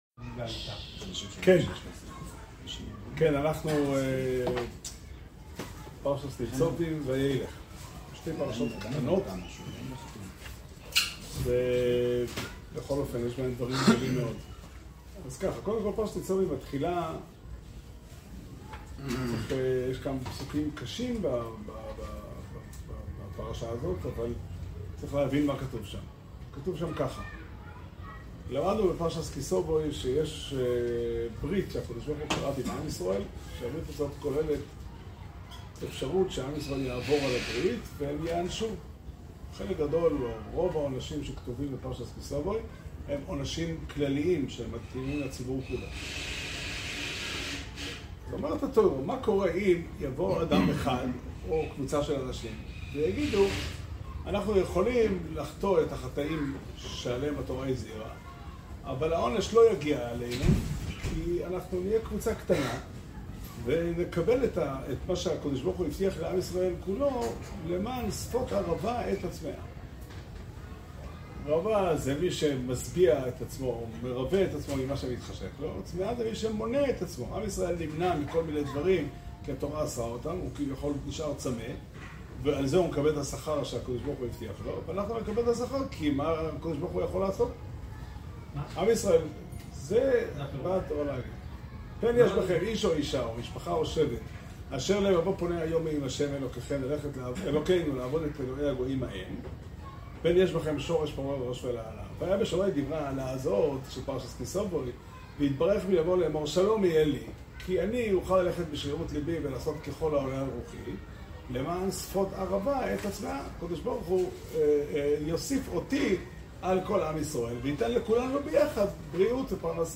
שיעור שנמסר בבית המדרש פתחי עולם בתאריך כ' אלול תשפ"ד